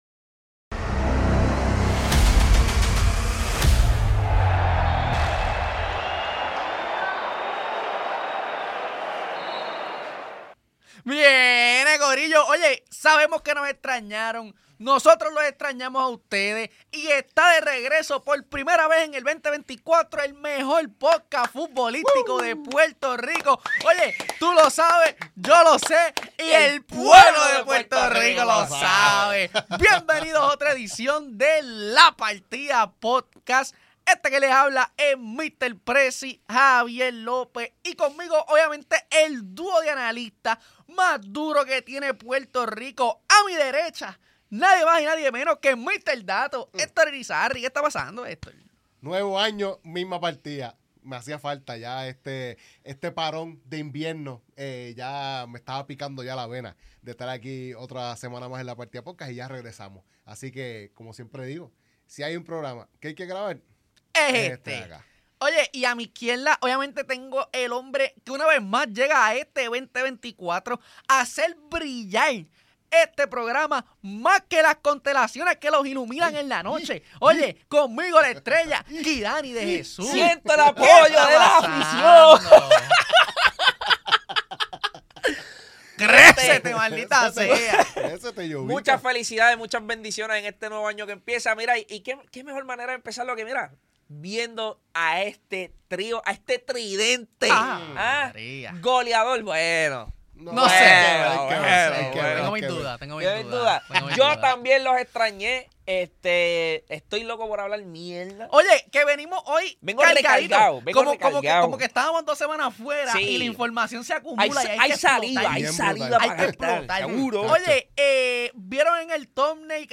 Grabado en GW5 Studio Subscribe Next Episodio 60: The Best?